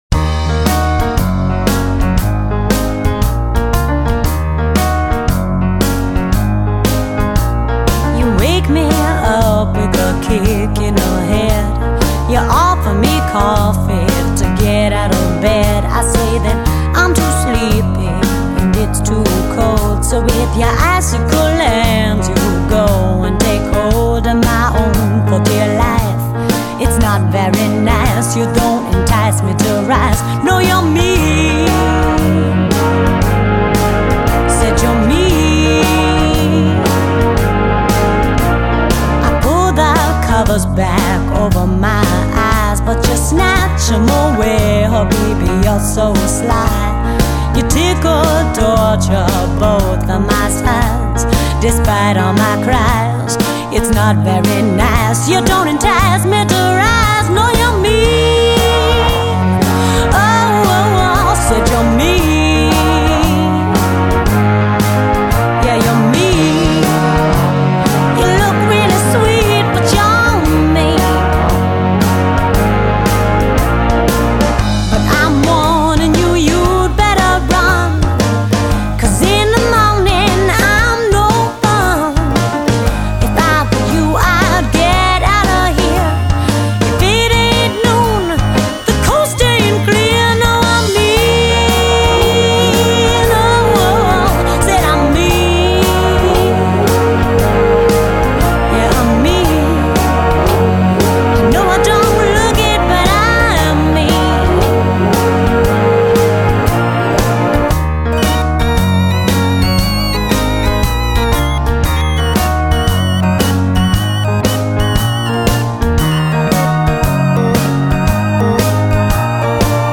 jazzy pop songs